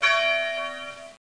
1 channel
glocke.mp3